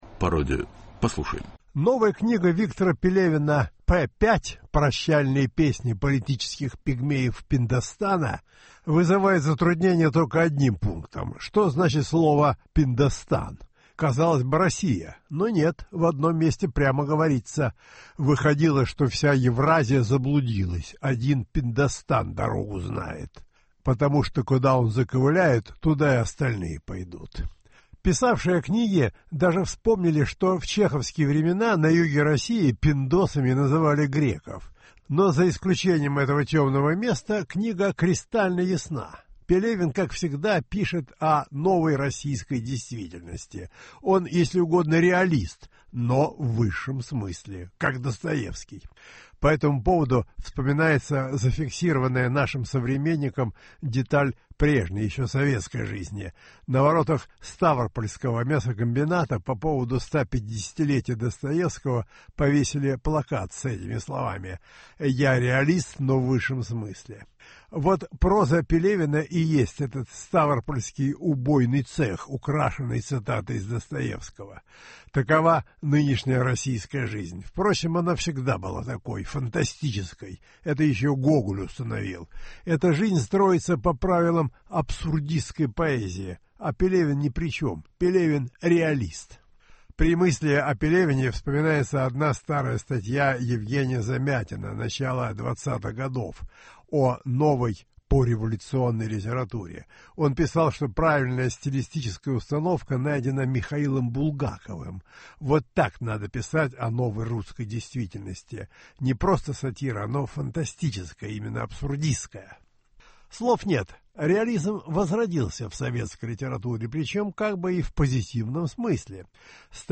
Последний роман Виктора Пелевина в радиоэссе Бориса Парамонова